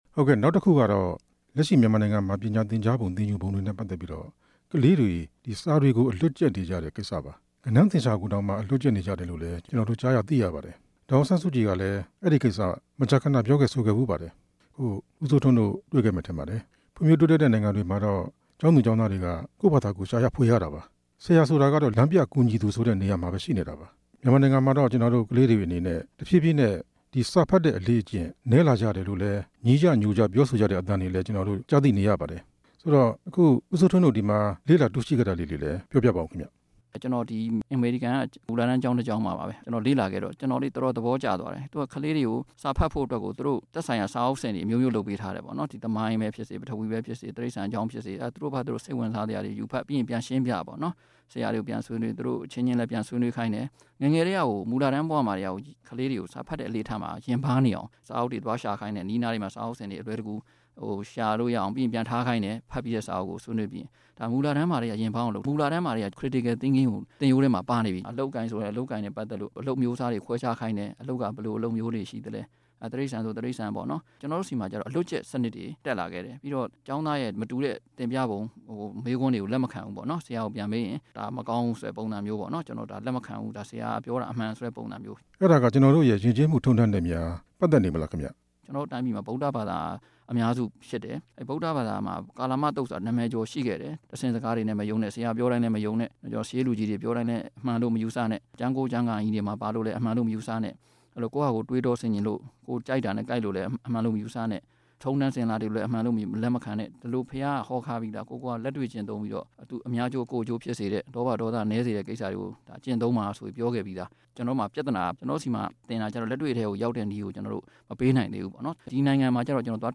တွေ့ဆုံမေးမြန်းချက် (ဒုတိယပိုင်း)